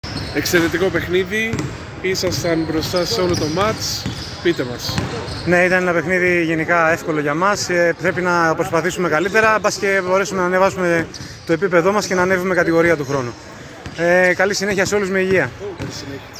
GAMES INTERVIEWS